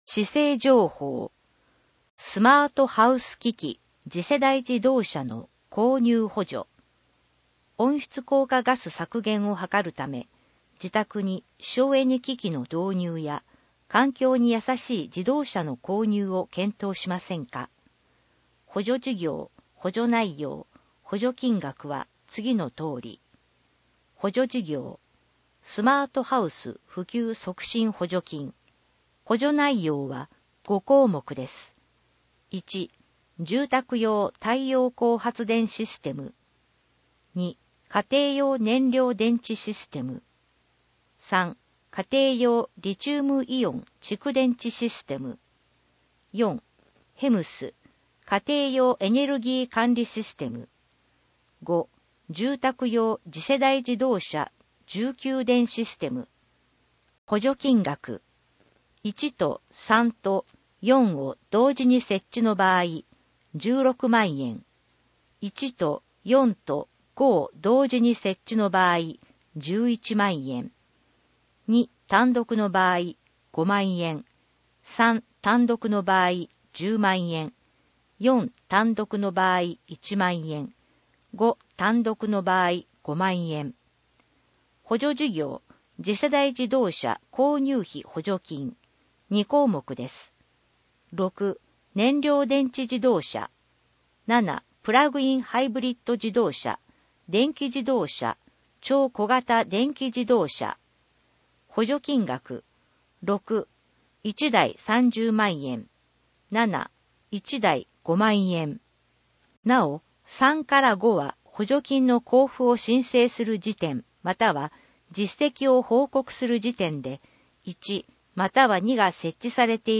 なお、以上の音声データは、「音訳ボランティア安城ひびきの会」の協力で作成しています。